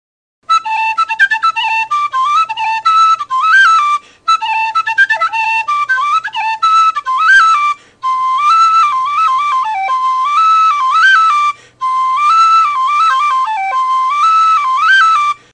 Tully Soliloquy Soprano C whistle
Non-pure with a nice chiffy character.
Sound clips of the whistle:
Glass of Beer played pretty fast just to show that this whistle can take it.
The chiff never gets in the way on this one.